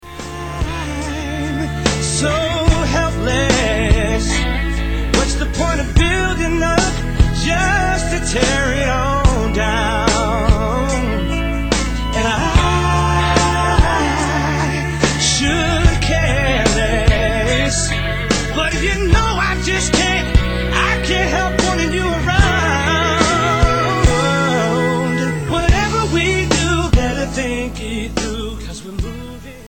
R & B Pop